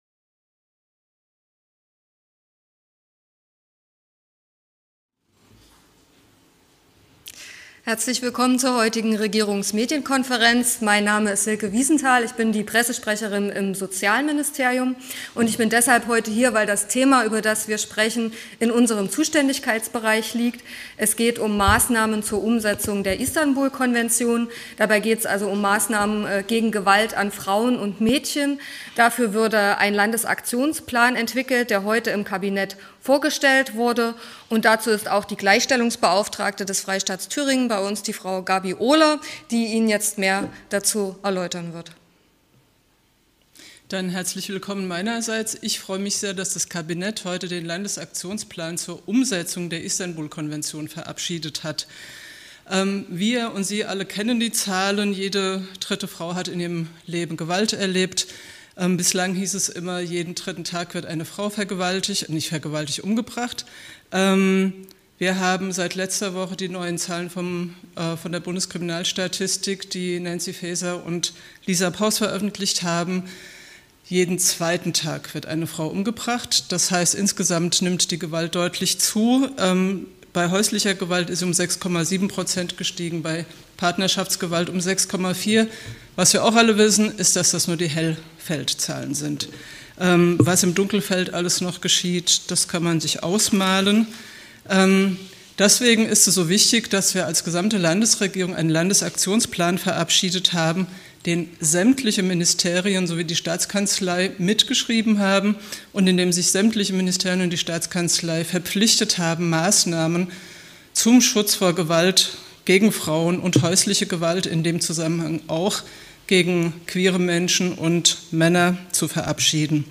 Regierungsmedienkonferenz des Freistaats Thüringen vom 18. Juni 2024